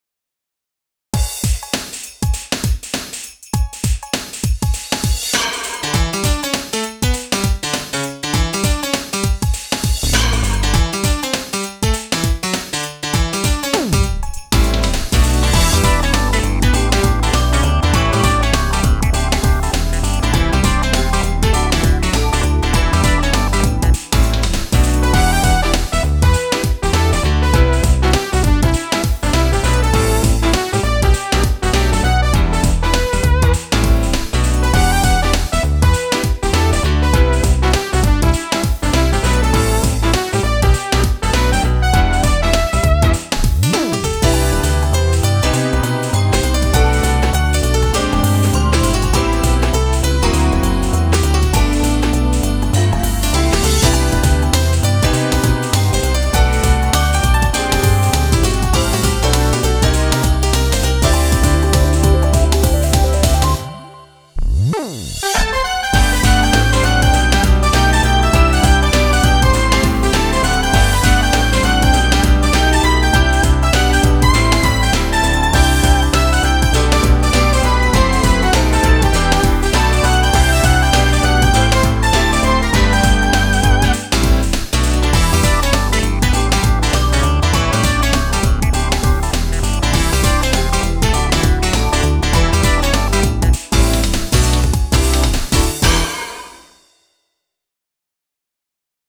BPM100
Audio QualityPerfect (High Quality)
A funky little ditty